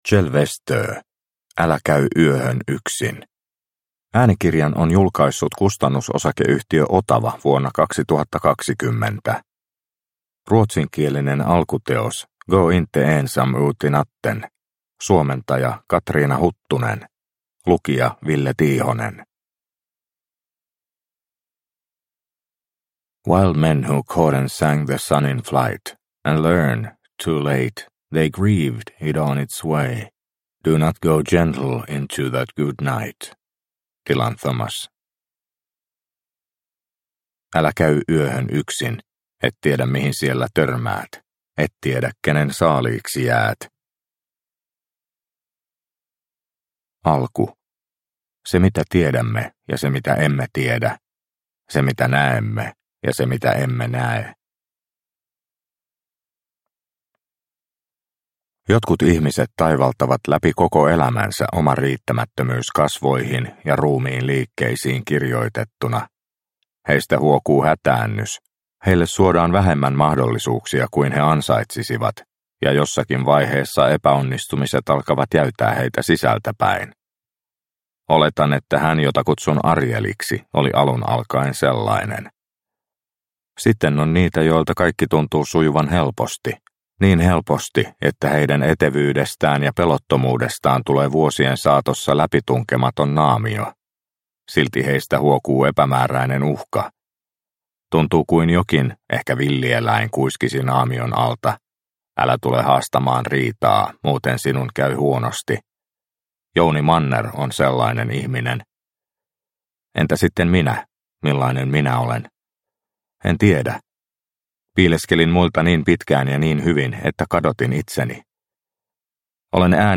Älä käy yöhön yksin – Ljudbok – Laddas ner